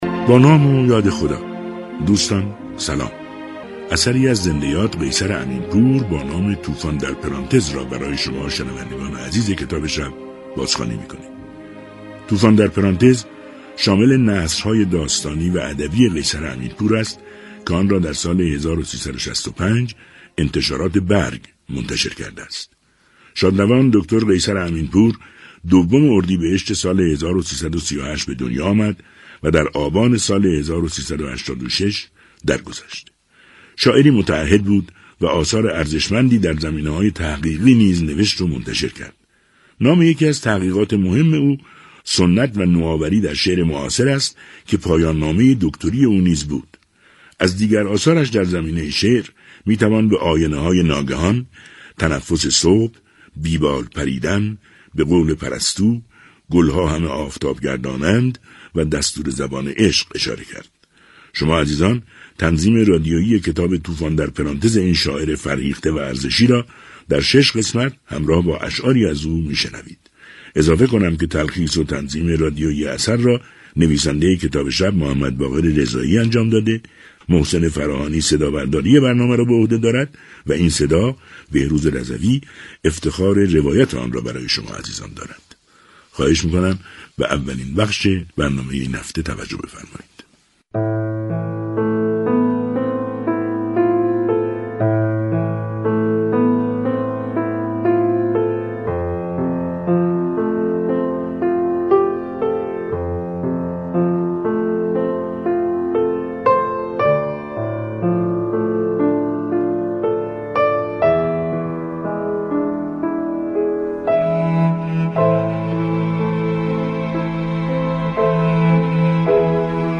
طوفان در پرانتز 🎙کتاب صوتی